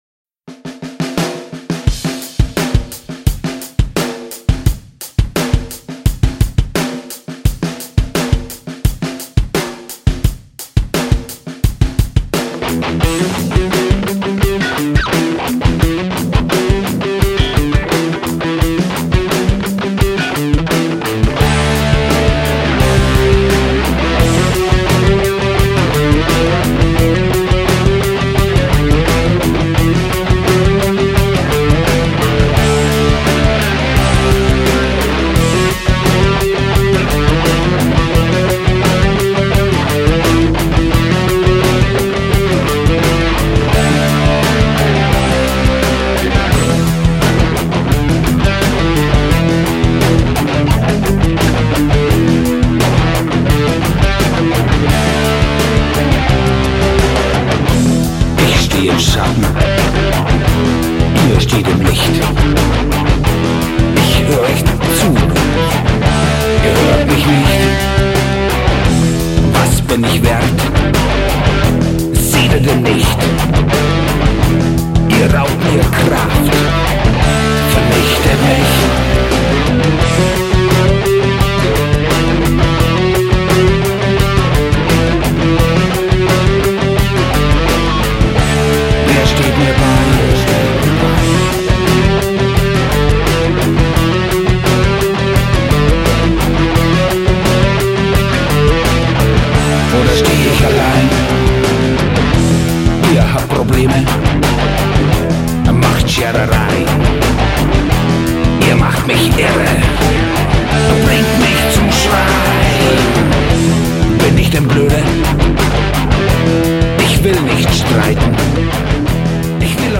Straight from the heart and dynamic